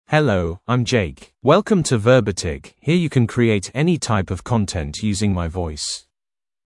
MaleEnglish (United Kingdom)
Jake is a male AI voice for English (United Kingdom).
Voice sample
Jake delivers clear pronunciation with authentic United Kingdom English intonation, making your content sound professionally produced.